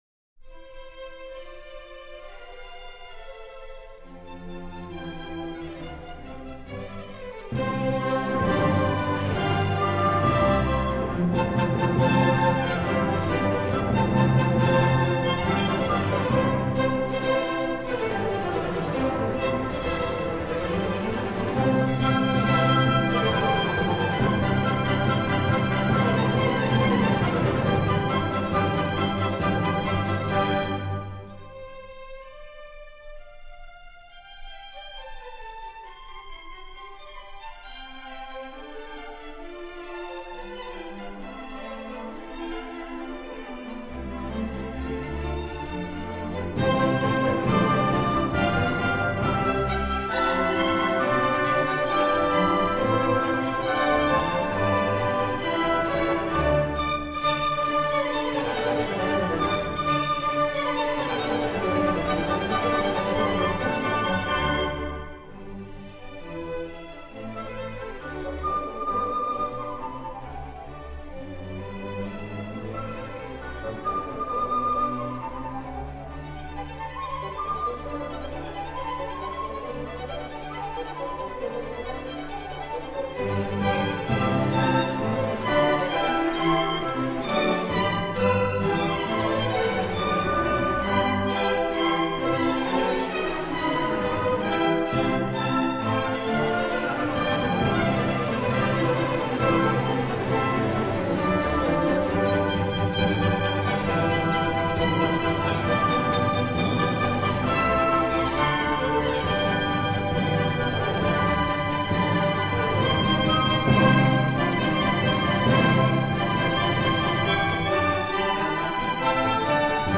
And then the finale, in four modest notes.
At six minutes into the amazement, the five galloping melodies align in a quintuple fugue.